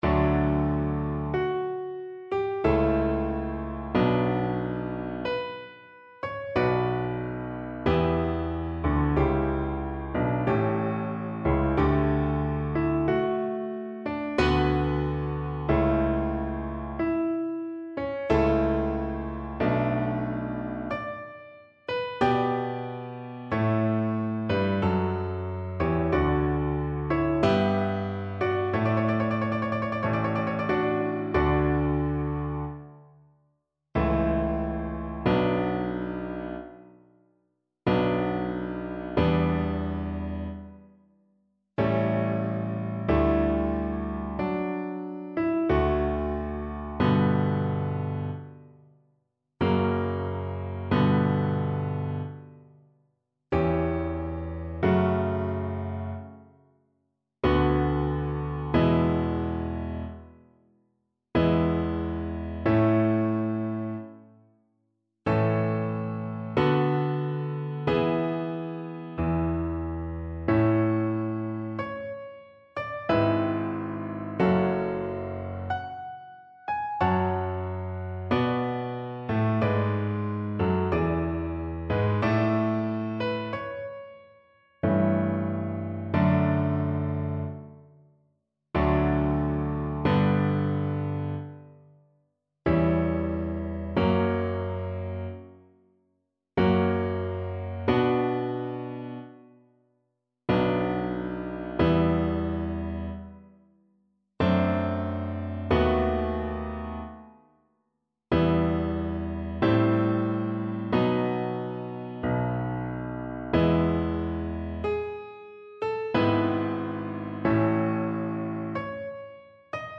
Classical Telemann, Georg Philipp Viola Concerto 1st Movement (Konzert fur Viola, Streicher und Basso continuo) Violin version
Violin
3/2 (View more 3/2 Music)
D major (Sounding Pitch) (View more D major Music for Violin )
Classical (View more Classical Violin Music)